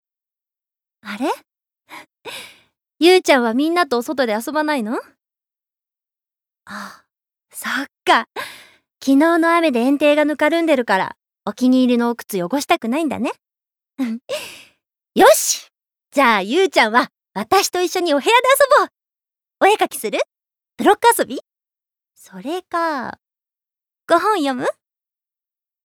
Voice Sample
ボイスサンプル
セリフ１